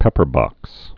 (pĕpər-bŏks)